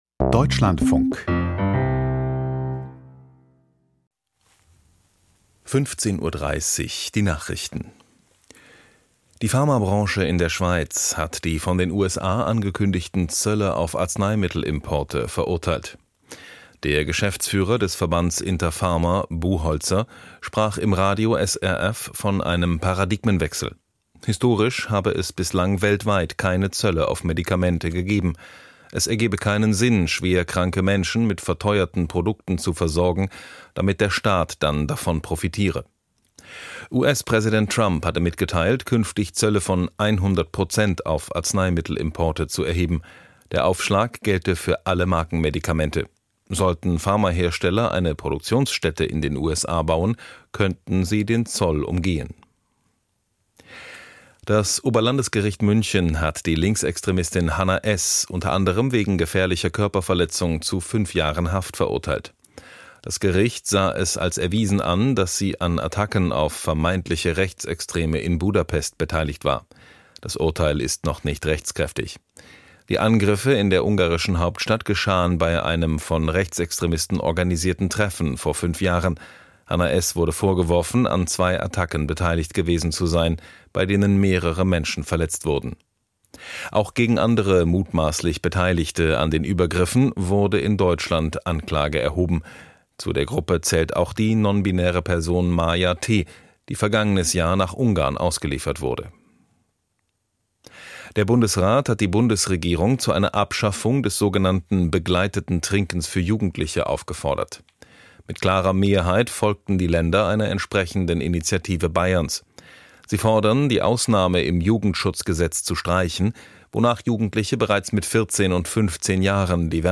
Die Nachrichten vom 26.09.2025, 15:30 Uhr